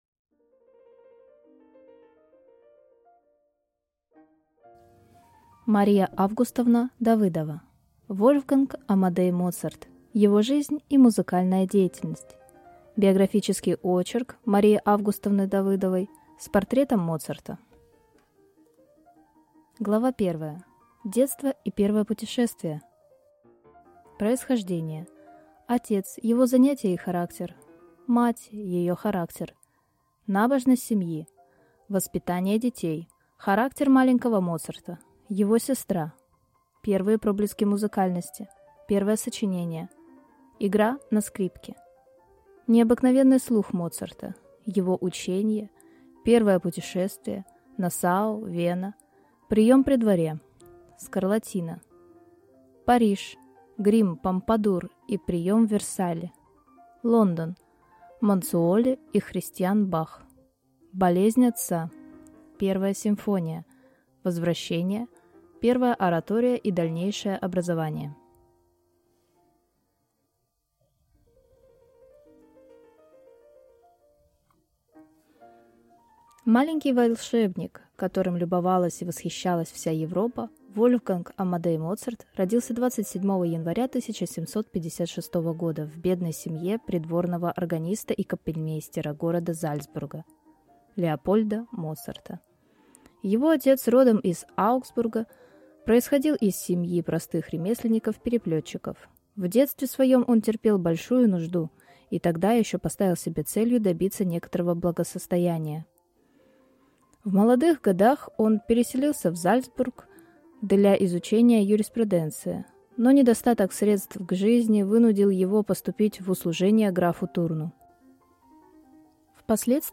Аудиокнига Вольфганг Амадей Моцарт. Его жизнь и музыкальная деятельность | Библиотека аудиокниг